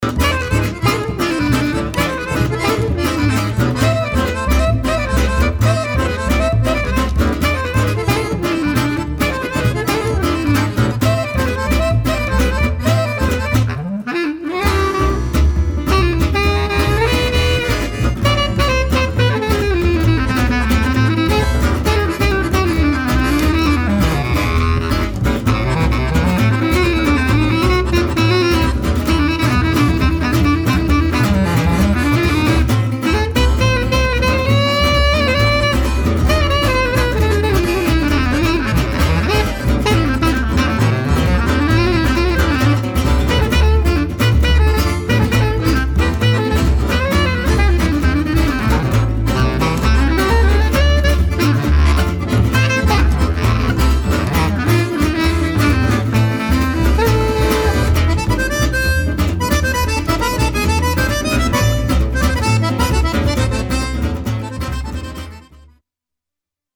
clarinette, clarinette basse
guitare
accordéon
contrebasse